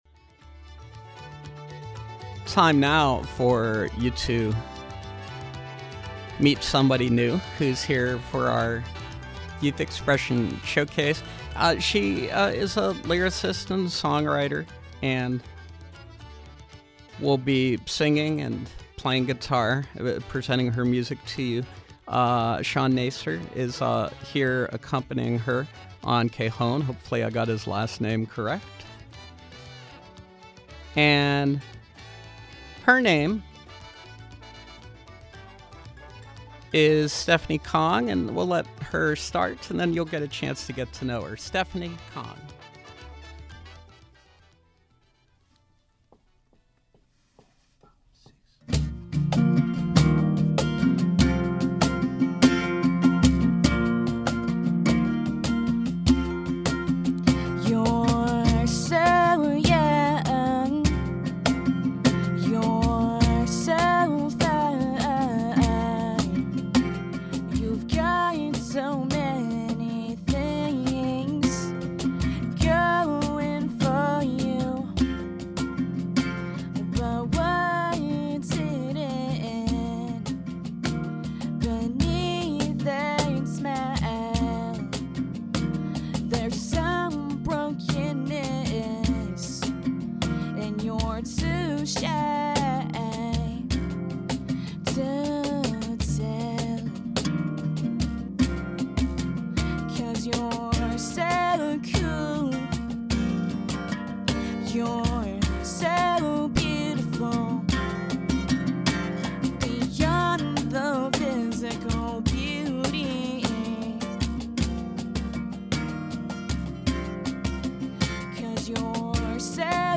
singer-songwriter
cajon